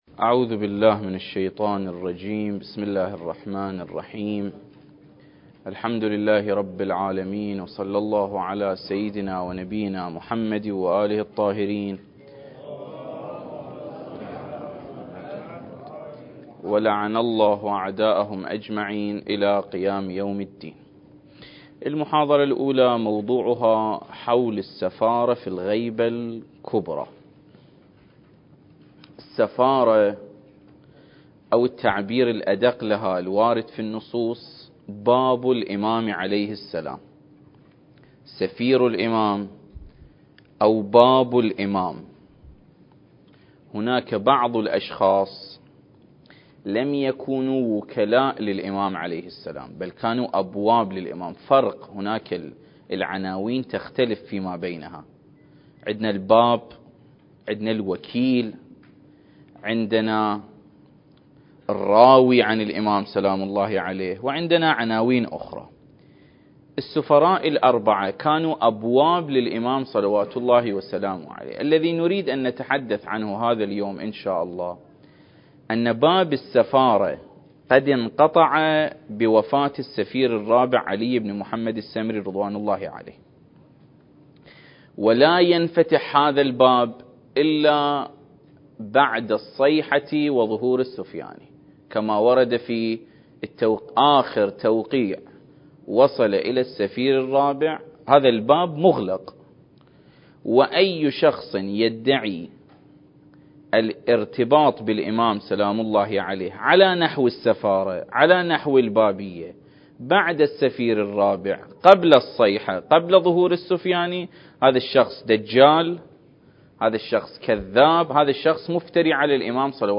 المكان: مؤسسة الإمام الحسن المجتبى (عليه السلام) - النجف الأشرف دورة منهجية في القضايا المهدوية